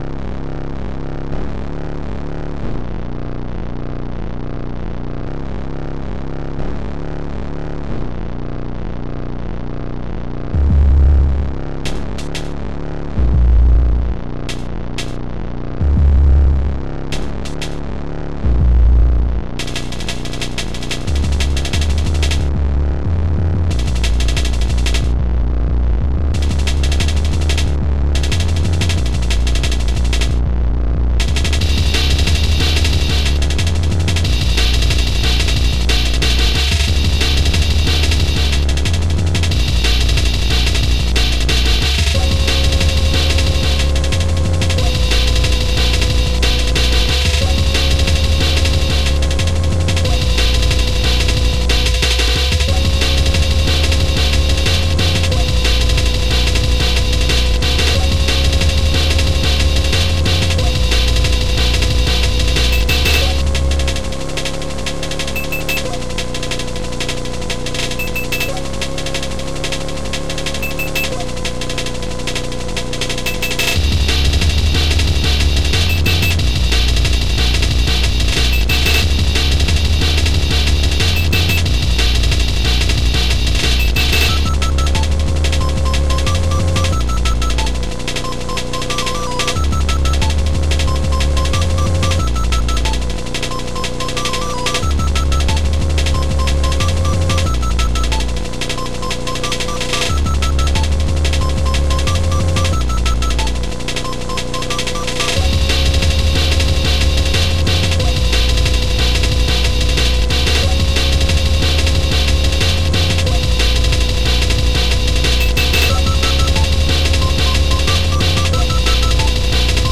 Impulse Tracker Module
ChipTune